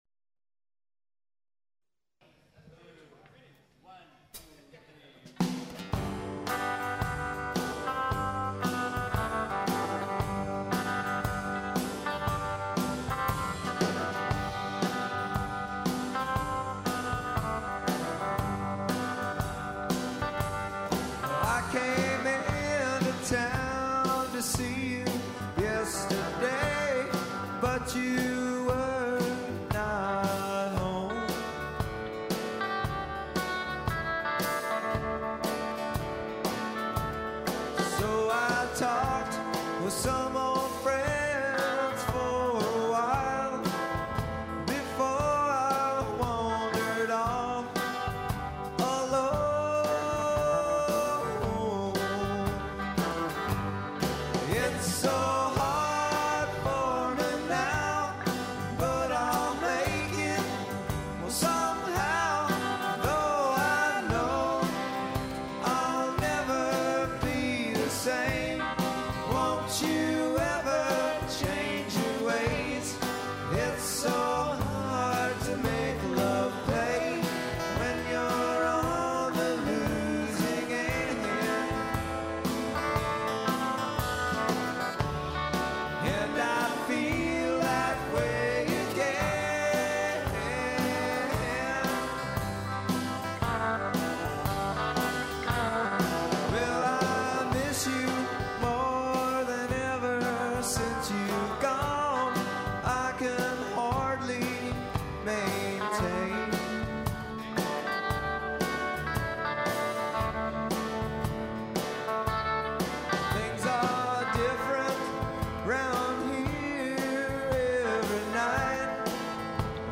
circa 1991 live at Bodie's downtown San Diego